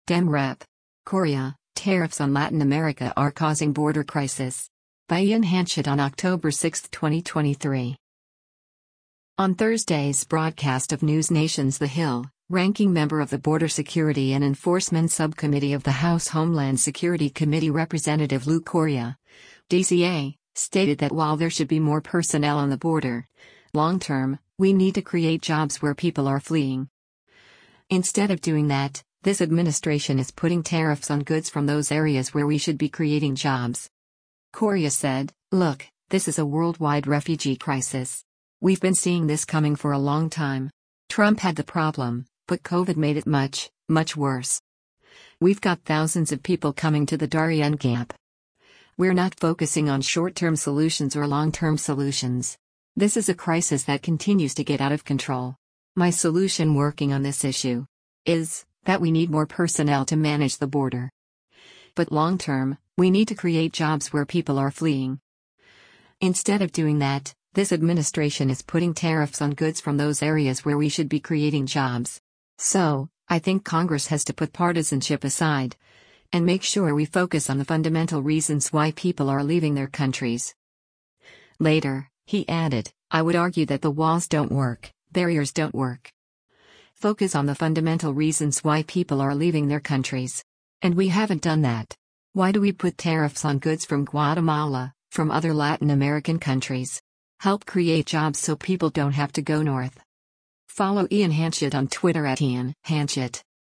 On Thursday’s broadcast of NewsNation’s “The Hill,” Ranking Member of the Border Security and Enforcement Subcommittee of the House Homeland Security Committee Rep. Lou Correa (D-CA) stated that while there should be more personnel on the border, “long-term, we need to create jobs where people are fleeing. Instead of doing that, this administration is putting tariffs on goods from those areas where we should be creating jobs.”